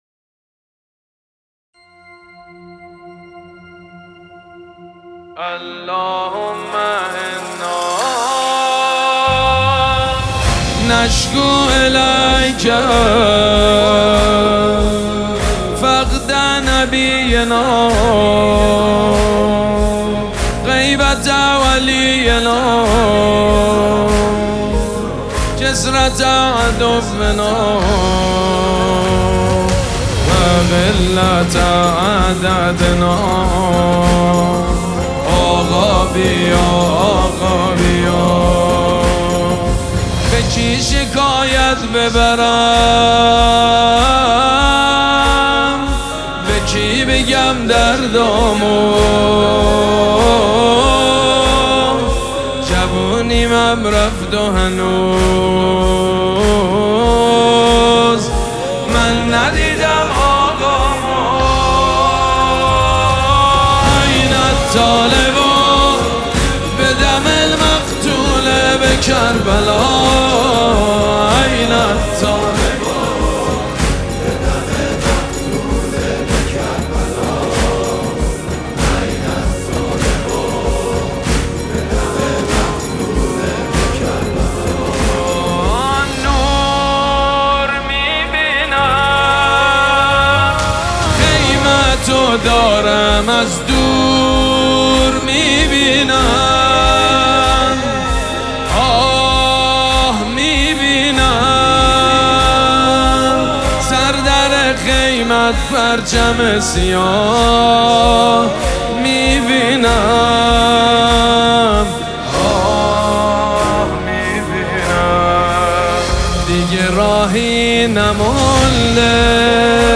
مراسم عزاداری شام غریبان محرم الحرام 1445
مناجات
حاج سید مجید بنی فاطمه